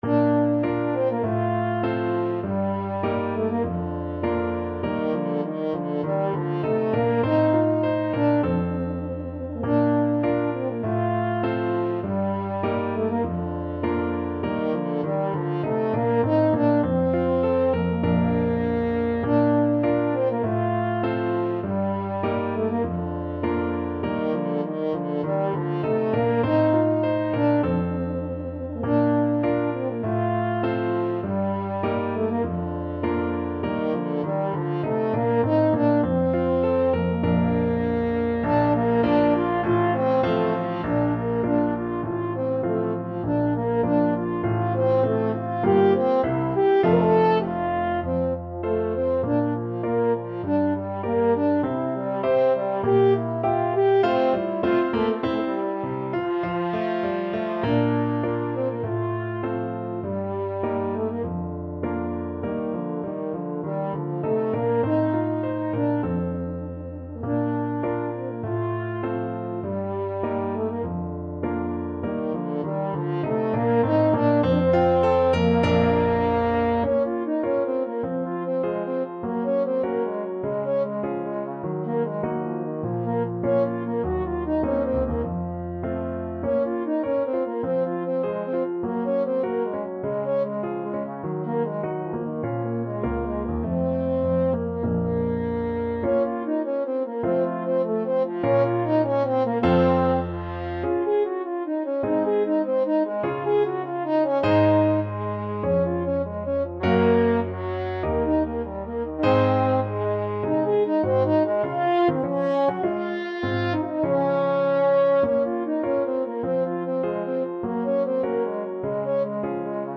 French Horn
Bb major (Sounding Pitch) F major (French Horn in F) (View more Bb major Music for French Horn )
~ = 100 Allegretto
Classical (View more Classical French Horn Music)
rameau_gavotte_1_2_HN.mp3